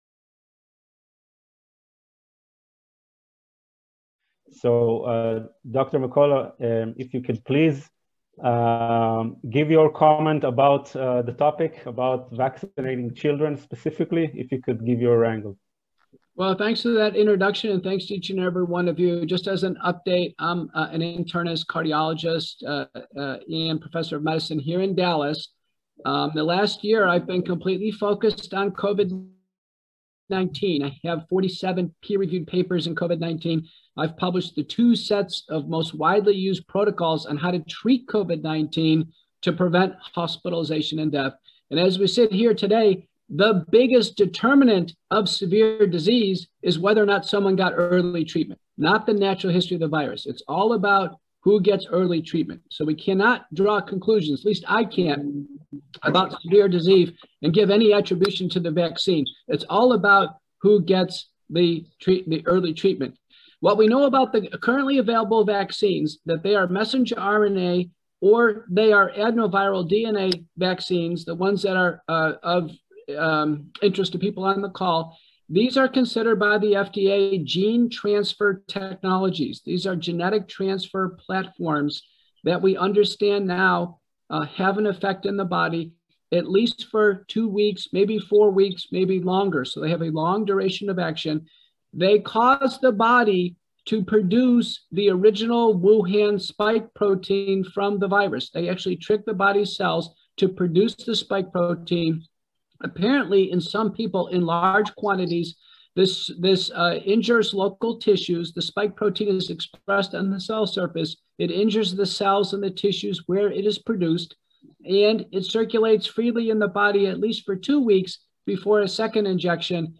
עדות נאמנה 4: פרופ' פיטר מקולה קרדיולוג בעל שם עולמי משמיע חוות דעת רפואית בפני בית דין (רבני) בישראל בנו...